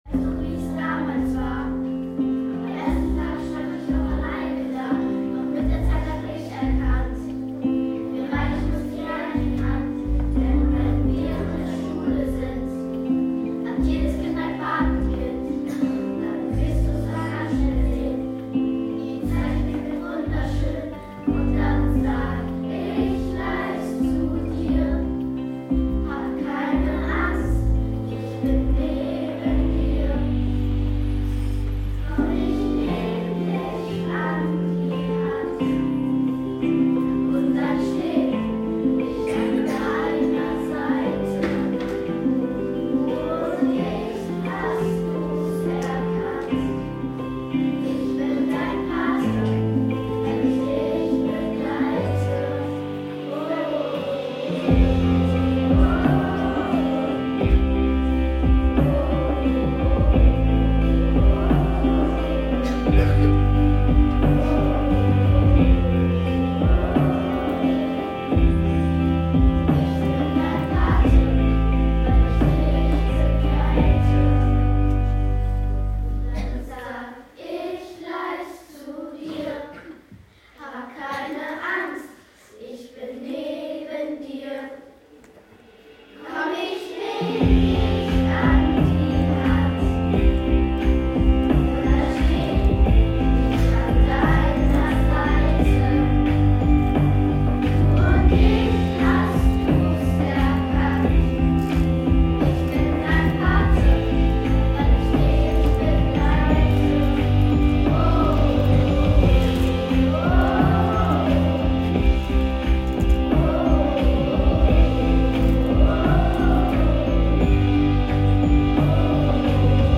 Unter anderem wurde unser Patenlied gesungen, dass man hier auch hören kann!